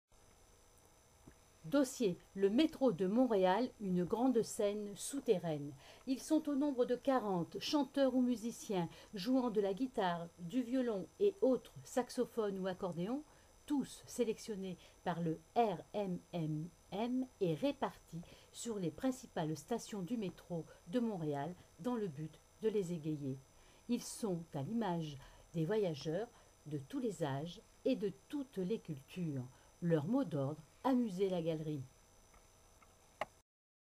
Métro_Montréal_1.mp3 (528.29 Ko)